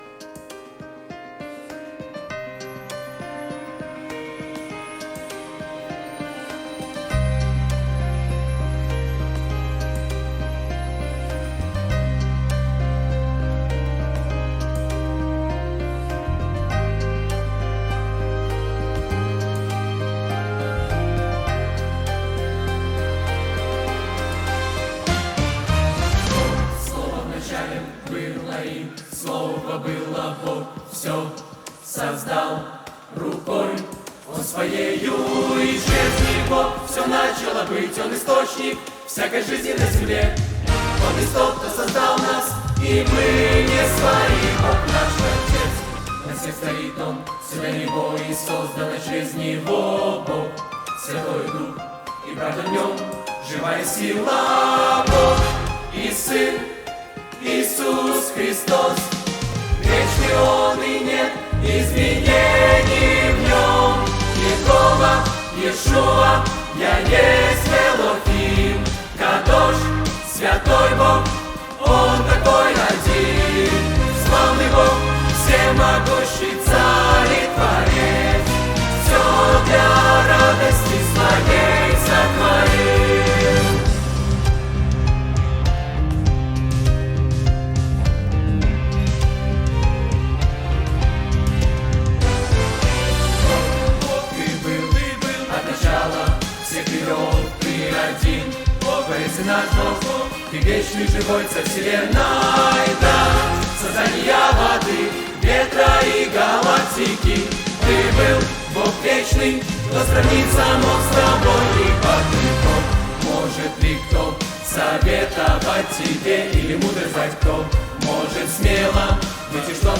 101 просмотр 178 прослушиваний 2 скачивания BPM: 102